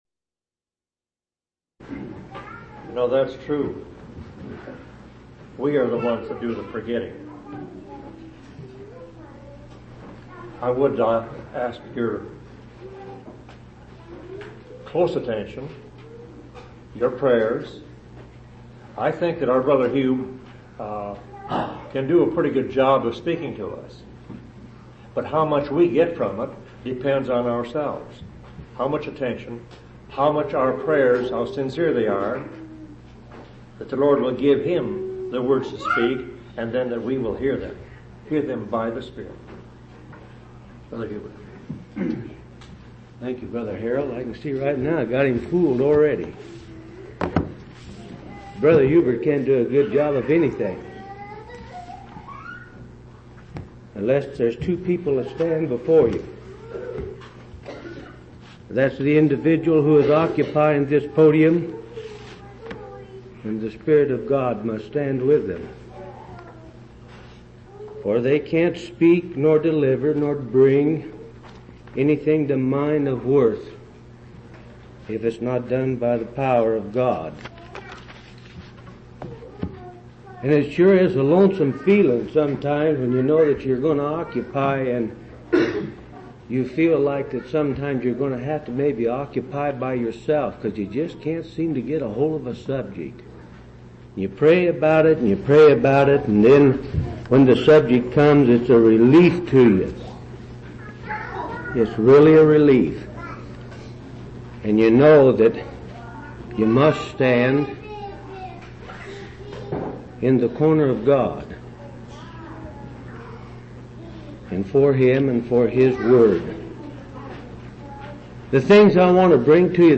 6/5/1983 Location: Phoenix Local Event